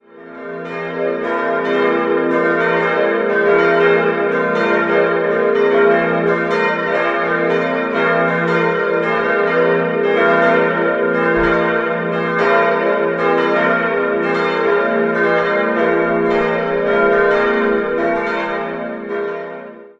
4-stimmiges Geläut: f'-as'-b'-c'' Die große Glocke wurde 1960, die beiden mittleren 1951 von Friedrich Wilhelm Schilling in Heidelberg gegossen. Die kleinste stammt aus der 1. Hälfte des 14. Jahrhunderts und wurde in Nürnberg gegossen.